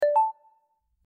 Text Message Receive
Text_message_receive.mp3